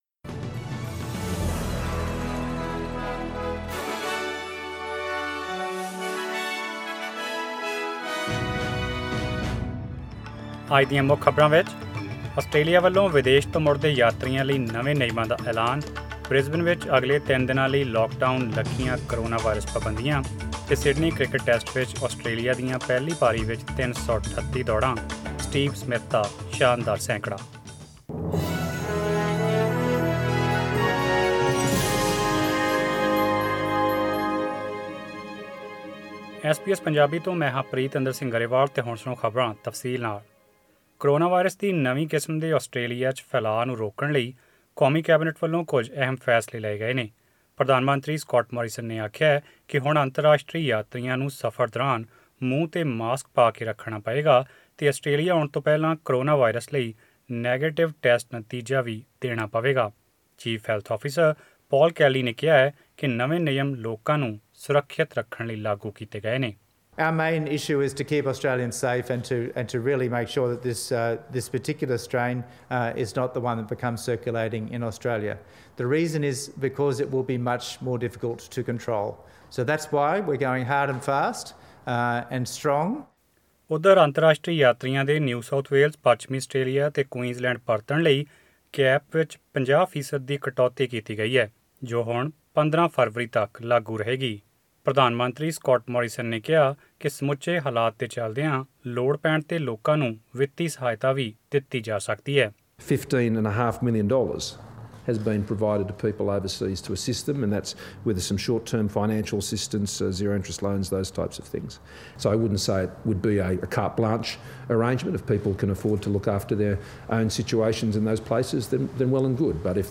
Australian News in Punjabi: 8 January 2021